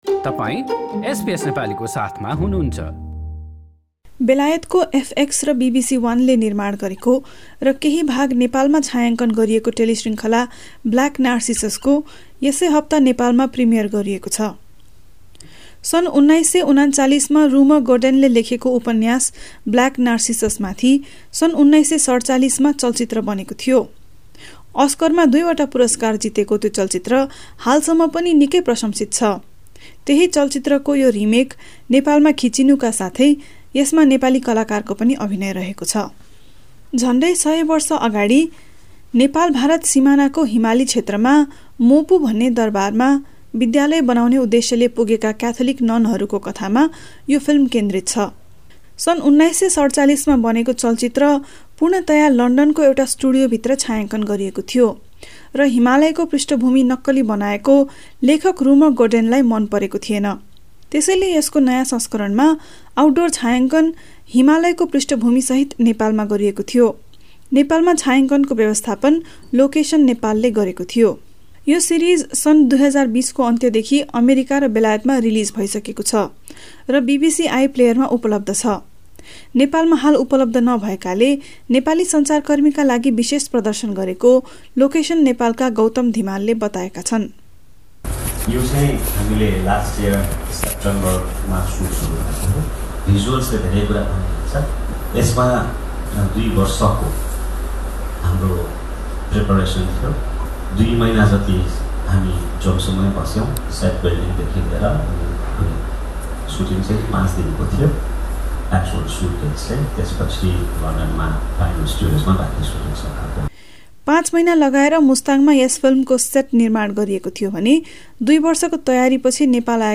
This news report is available in the Nepali language section of our website.